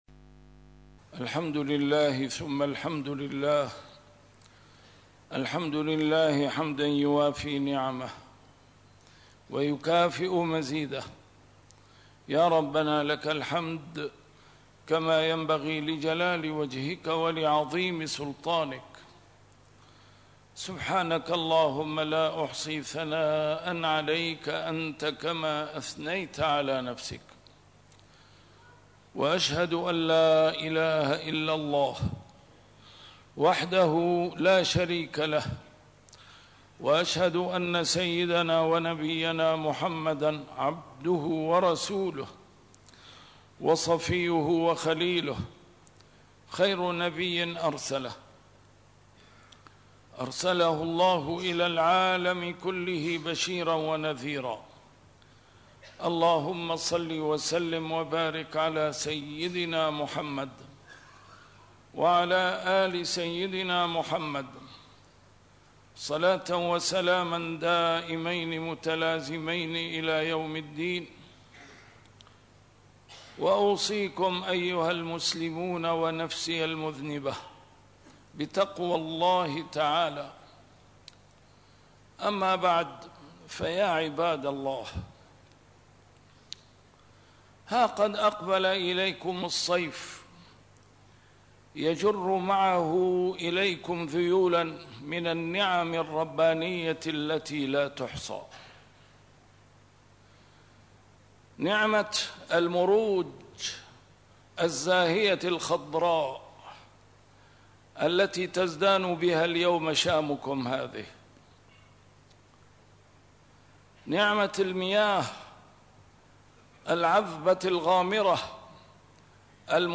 A MARTYR SCHOLAR: IMAM MUHAMMAD SAEED RAMADAN AL-BOUTI - الخطب - إياكم .. وكفر النعمة